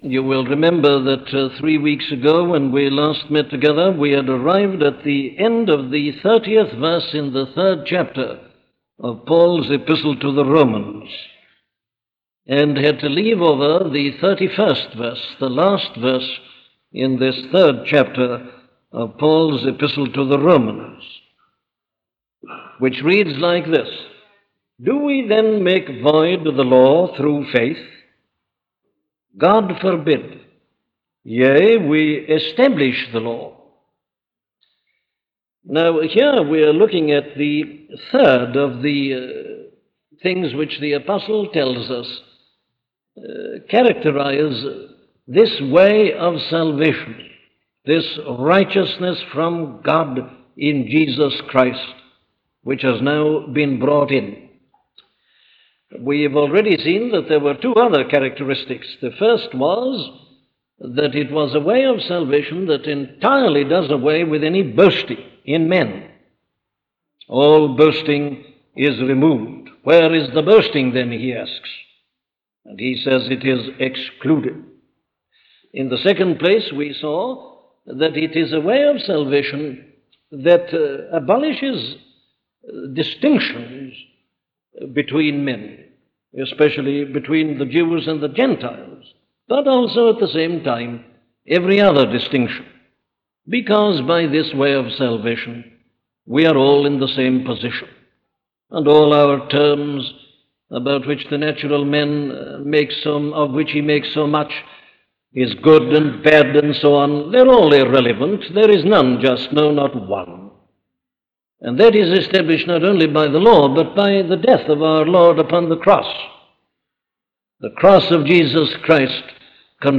The Law Established - a sermon from Dr. Martyn Lloyd Jones
Listen to the sermon on Romans 3:31 'The Law Established' by Dr. Martyn Lloyd-Jones